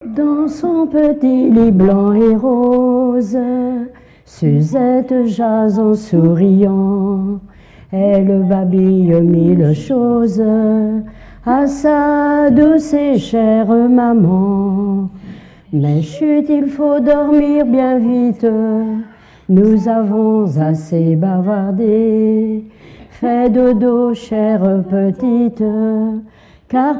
chansons traditionnelles lors d'un concert associant personnes ressources et continuateurs
Pièce musicale inédite